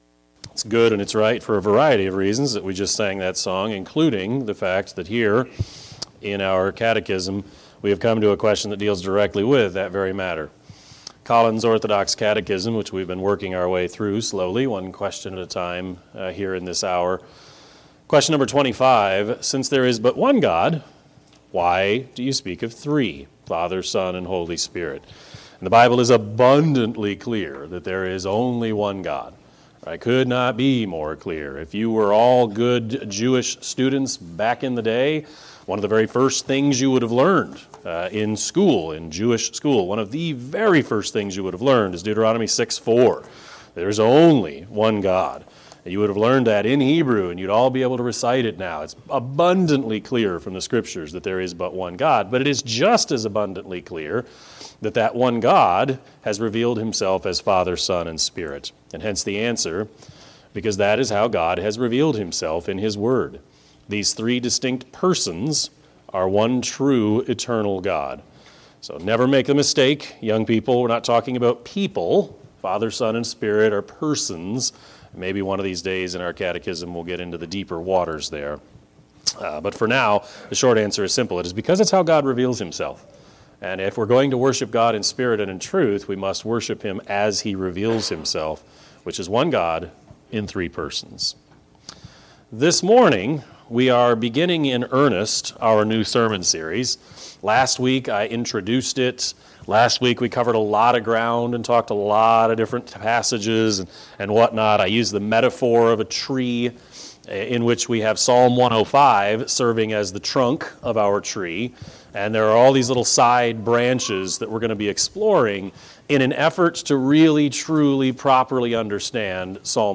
Sermon-5-1-22-Edit.mp3